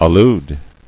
[al·lude]